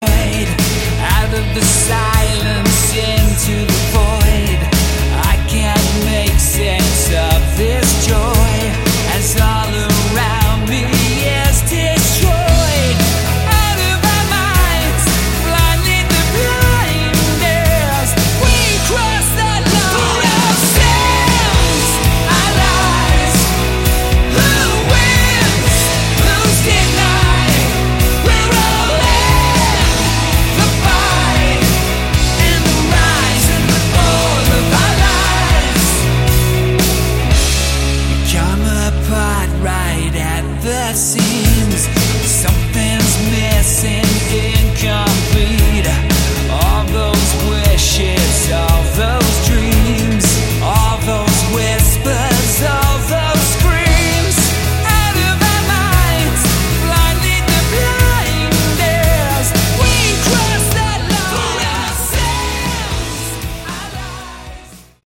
Category: Melodic Hard Rock
vocals
guitar
drums
keyboards